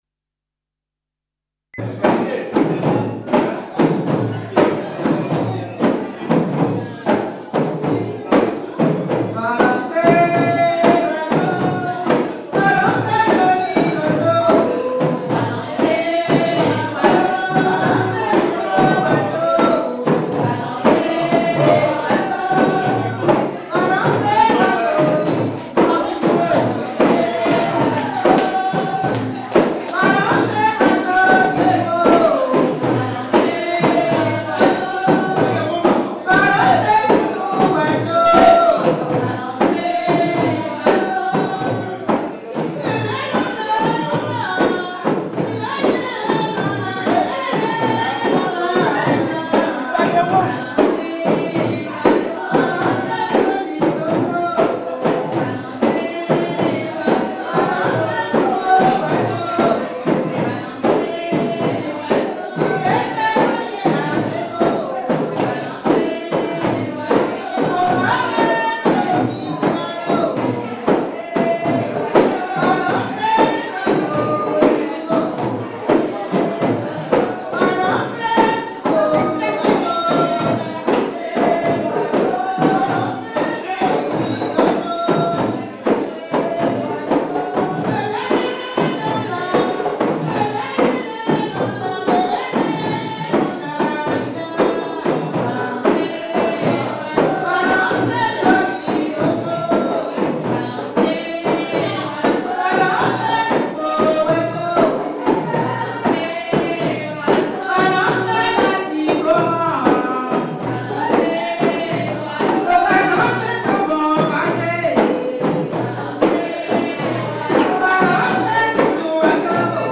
La chanteuse invite les danseurs à imiter un oiseau.
danse : grajé (créole)
Pièce musicale inédite